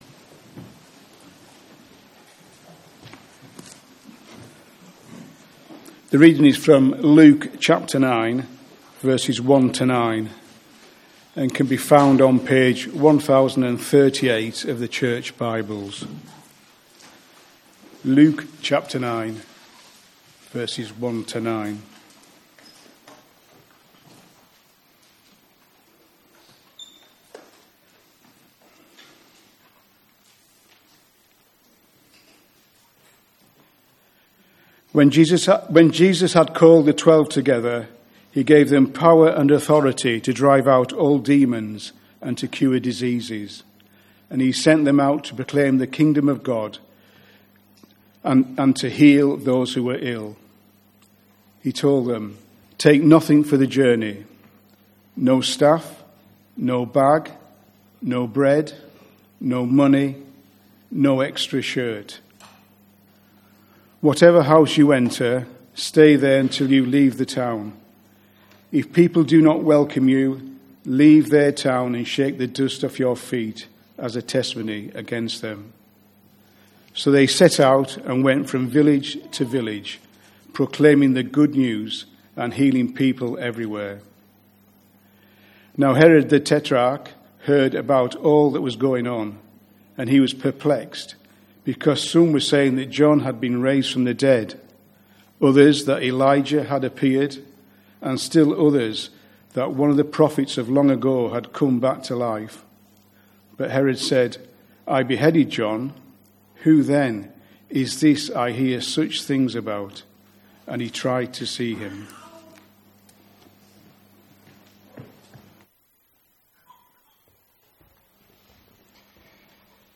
Service Type: Morning Service 11:15